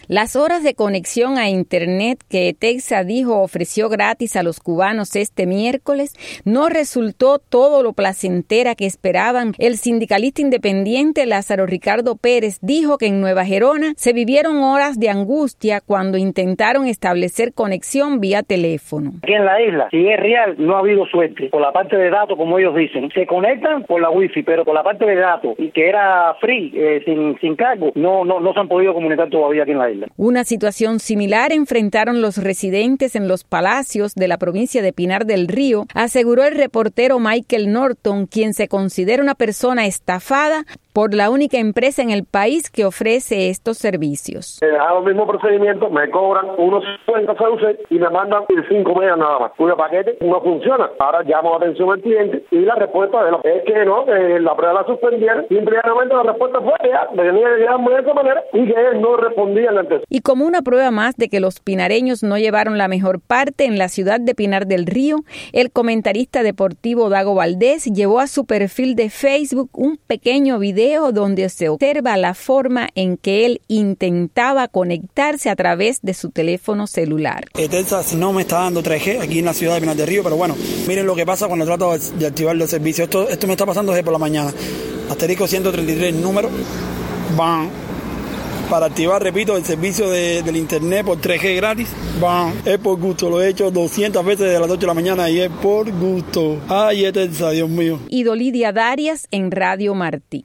Cubanos narran su experiencia en segunda prueba de Internet de ETECA